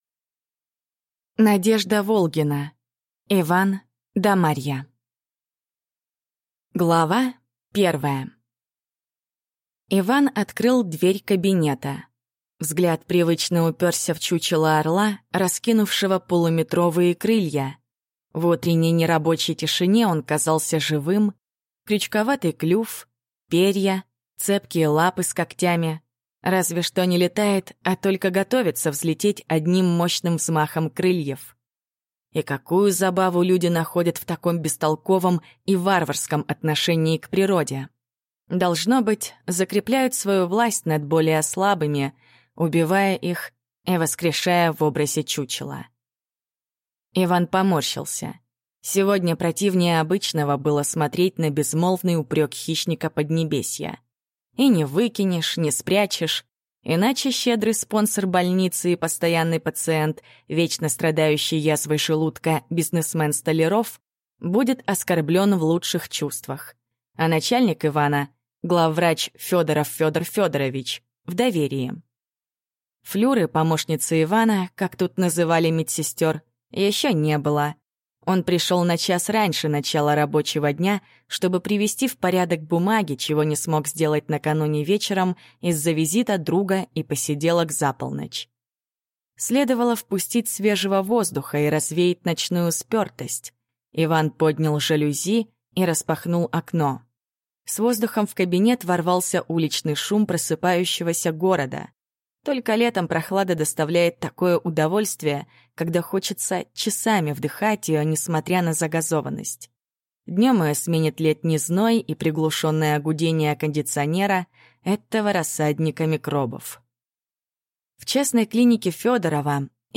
Аудиокнига Иван-да-Марья | Библиотека аудиокниг